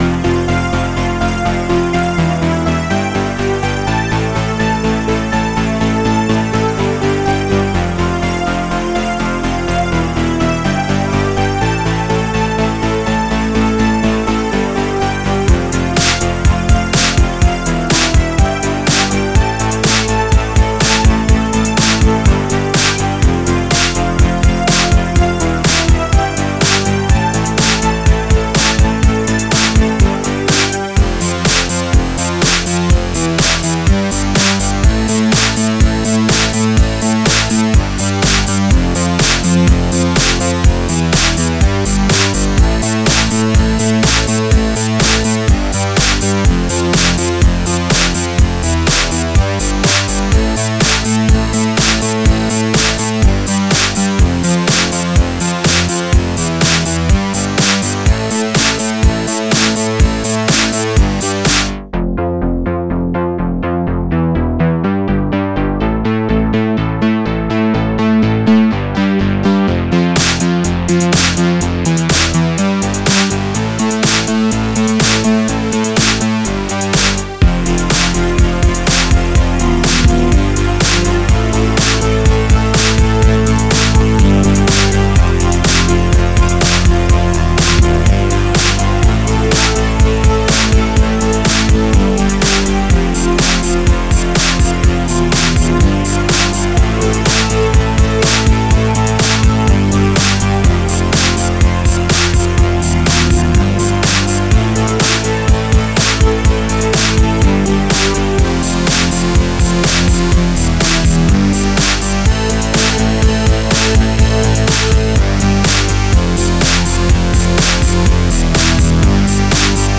Жанр: Electronic, Italo Disco, Synth Pop, New Wave